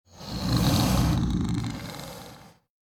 PixelPerfectionCE/assets/minecraft/sounds/mob/guardian/elder_idle2.ogg at mc116
elder_idle2.ogg